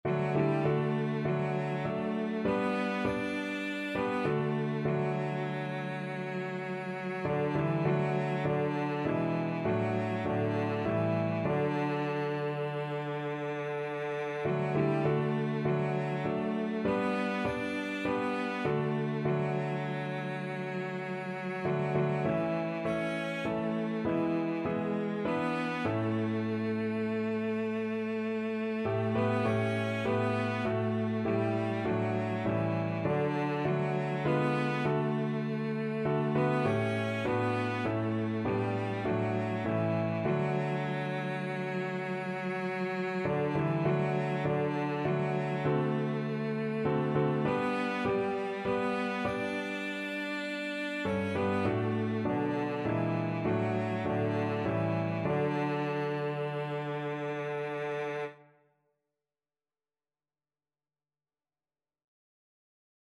Cello
6/4 (View more 6/4 Music)
D major (Sounding Pitch) (View more D major Music for Cello )
Classical (View more Classical Cello Music)